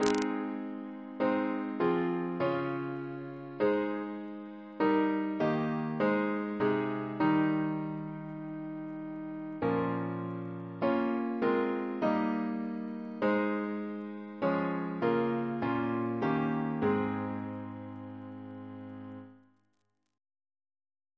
Double chant in A♭ Composer: F. A. Gore Ouseley (1825-1889) Reference psalters: ACB: 21; PP/SNCB: 32; RSCM: 106